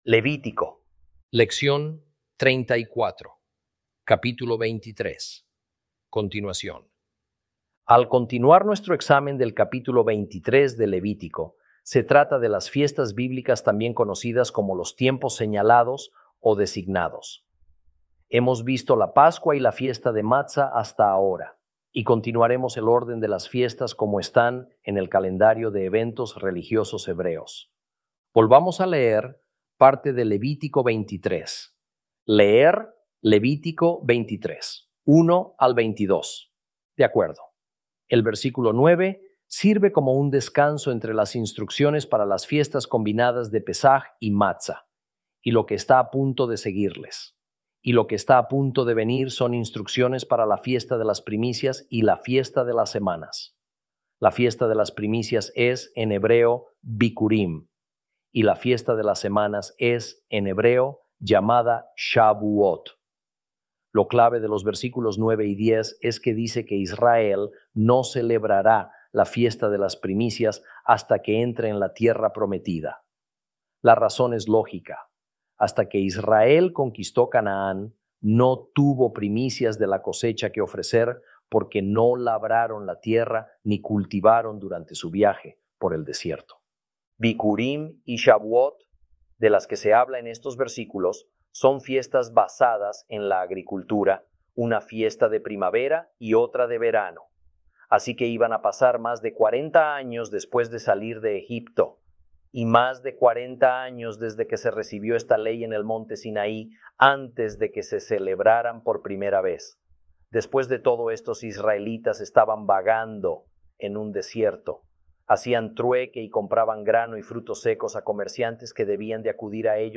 Lección 34 – Levítico 23